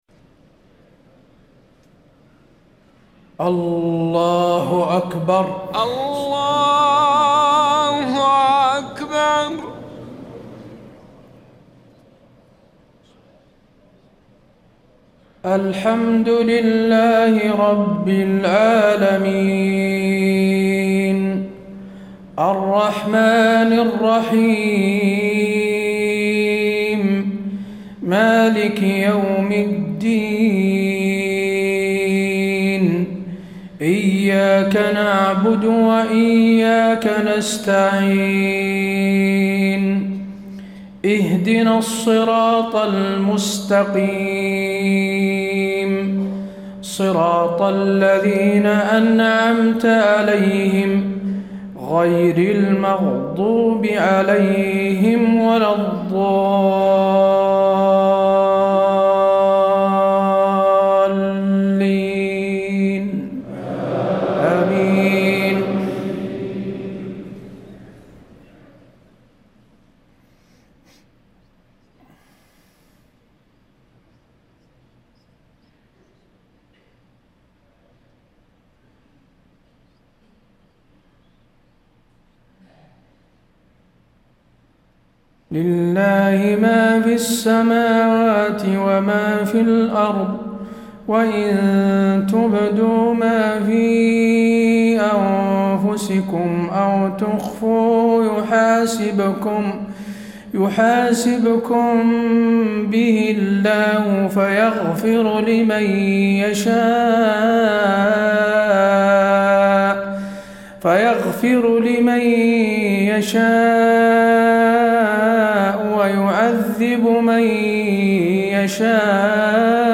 صلاة المغرب 2-8-1434 خواتيم سورة البقرة 284-286 > 1434 🕌 > الفروض - تلاوات الحرمين